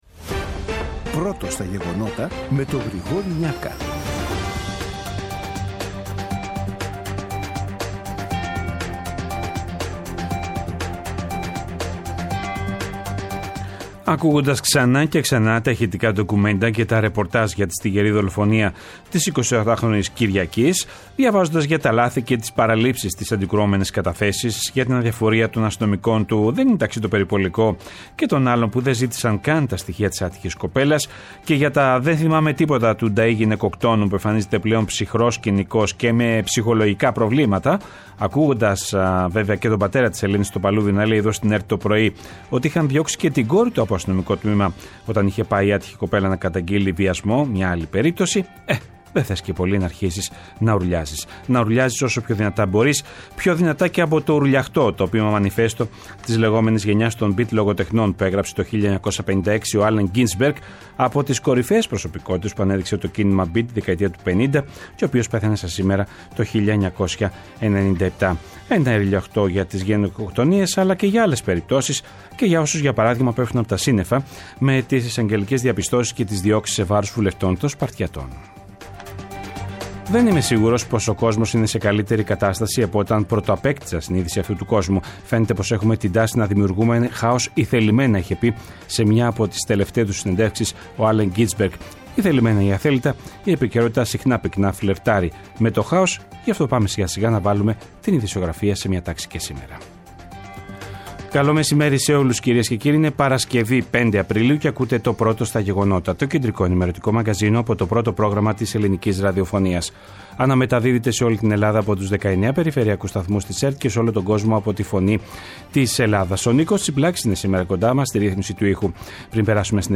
Το αναλυτικό ενημερωτικό μαγκαζίνο του Α΄ Προγράμματος, από Δευτέρα έως Παρασκευή στις 14:00. Με το μεγαλύτερο δίκτυο ανταποκριτών σε όλη τη χώρα, αναλυτικά ρεπορτάζ και συνεντεύξεις επικαιρότητας.